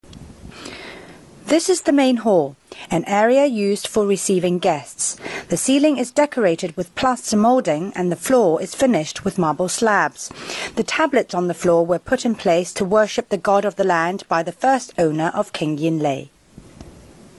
Vocal Description